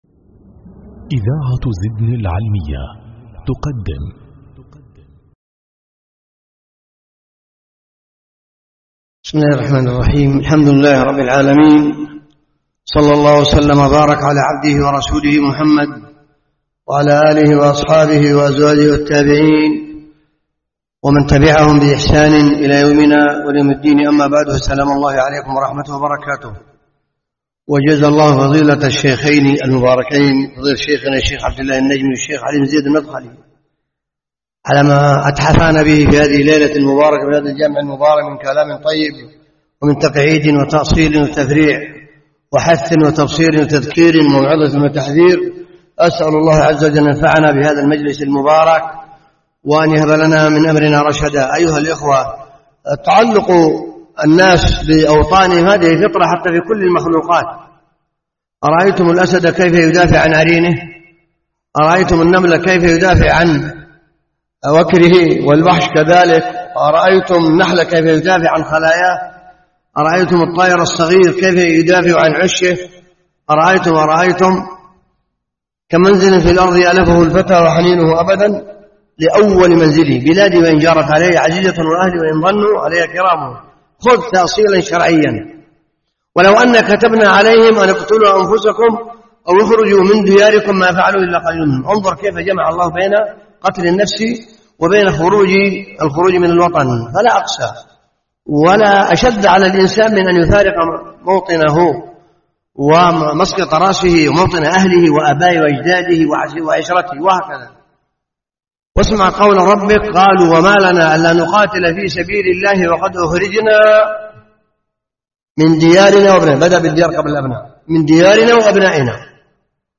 محاضرة
جامع أبوبكر الصديق بمحافظة العيدابي